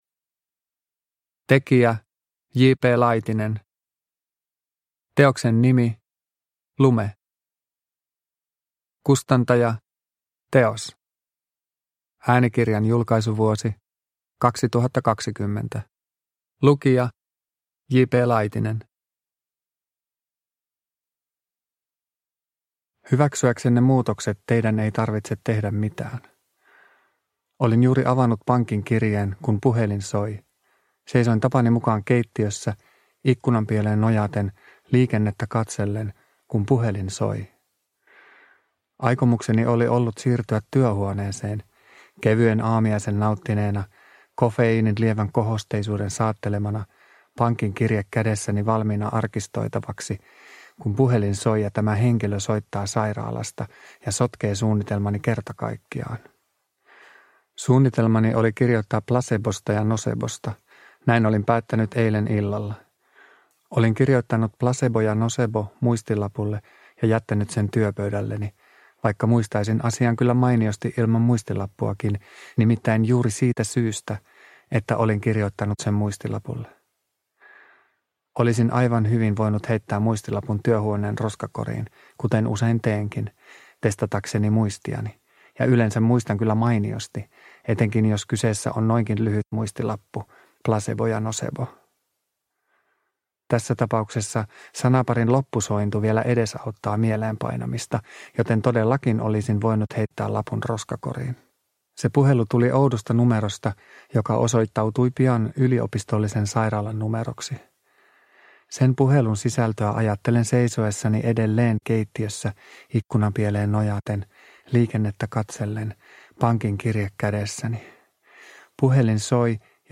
Lume – Ljudbok – Laddas ner